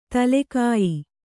♪ tale kāyi